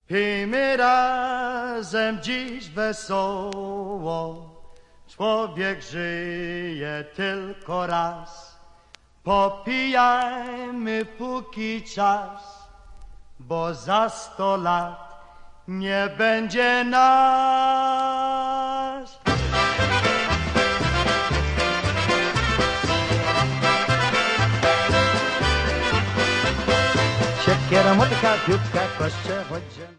Vocals & Drums
Trumpet
Clarinet & Sax
Accordion
Bass
Piano